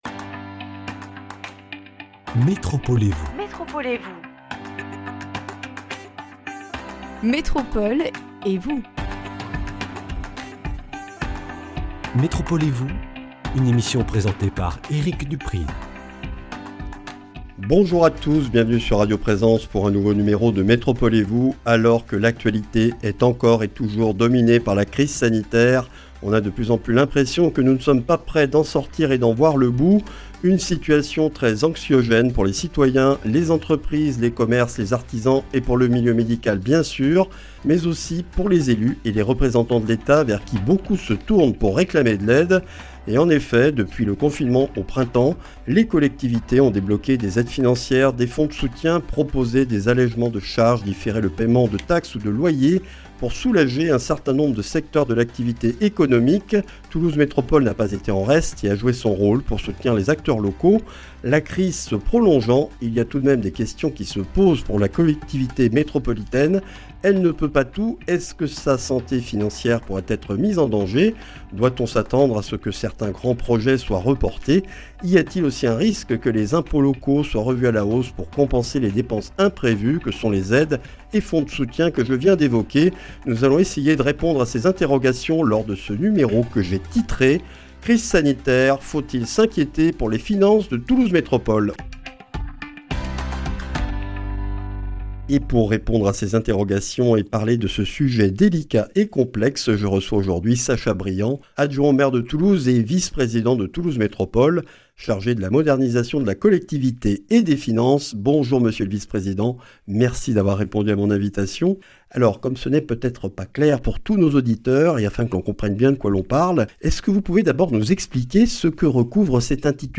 REDIFFUSION : Sacha Briand, adjoint au maire de Toulouse, vice-président de Toulouse Métropole chargé de la Modernisation de la collectivité et des Finances, conseiller régional d’Occitanie, est l’invité de ce numéro.